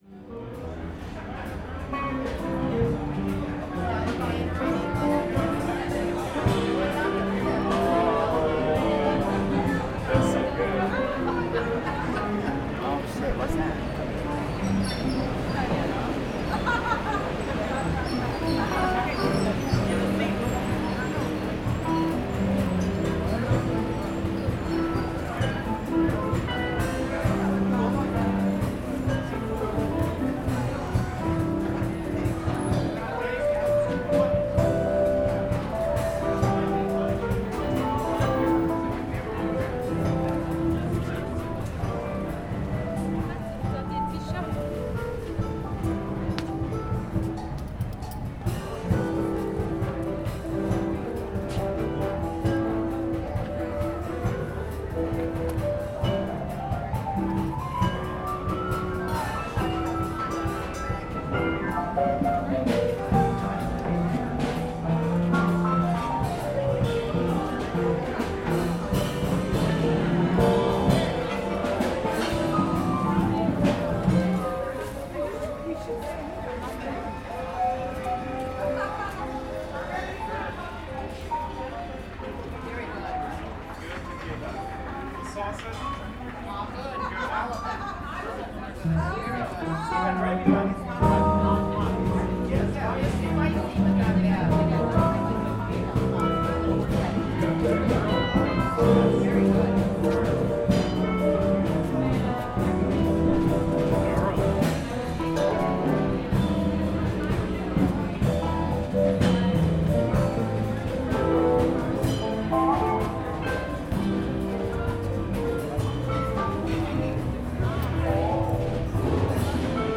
Walking in the french quarter
promenade_rue_1.mp3